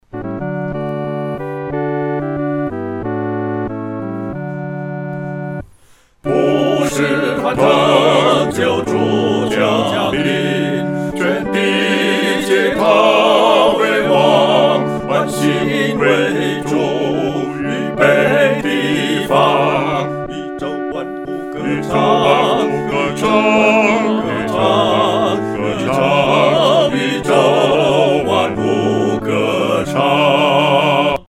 合唱（四声部）
普世欢腾-合唱（四声部）A.mp3